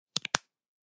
Звуки взбитых сливок
Баллончик накрыли крышкой